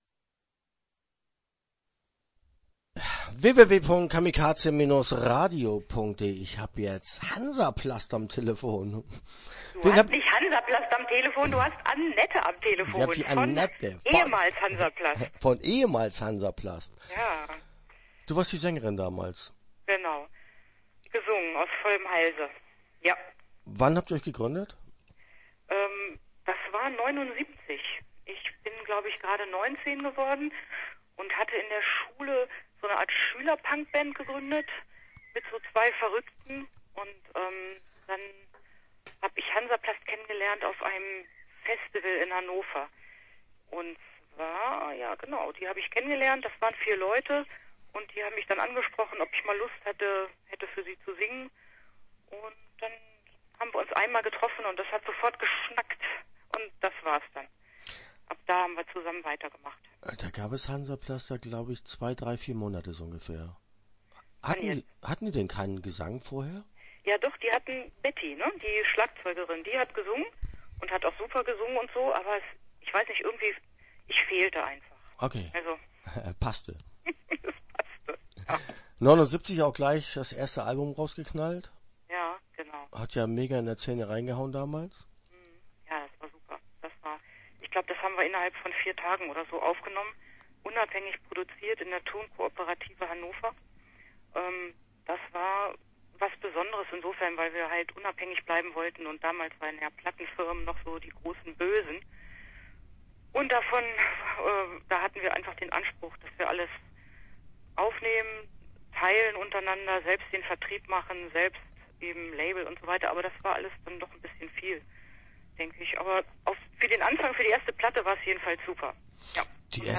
Hans-A-Plast - Interview Teil 1 (14:12)